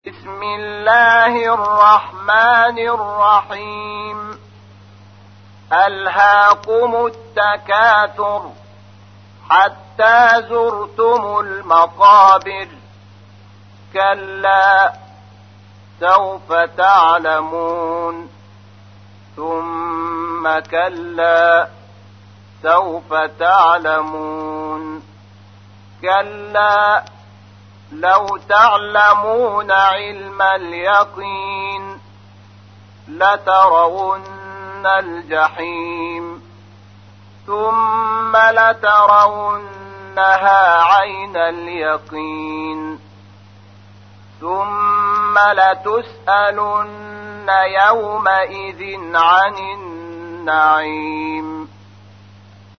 تحميل : 102. سورة التكاثر / القارئ شحات محمد انور / القرآن الكريم / موقع يا حسين